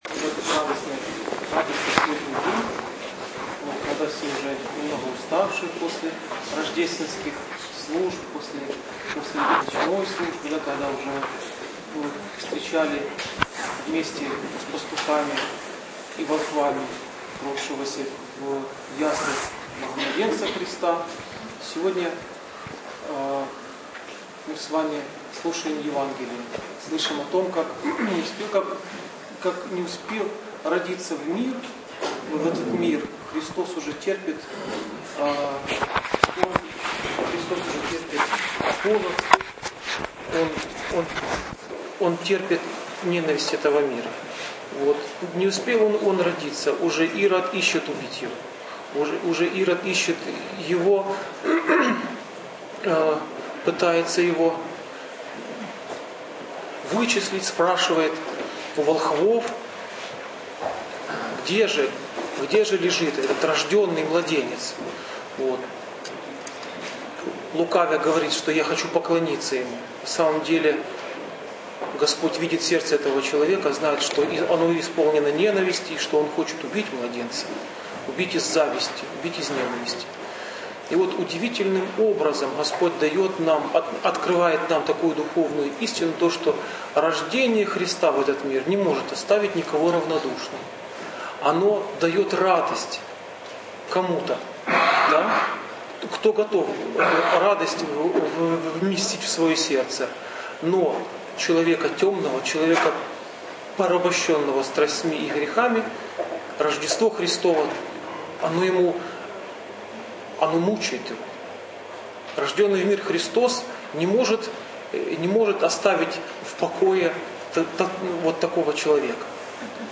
Новини , Проповіді Проповедь прот.